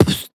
R - Foley 208.wav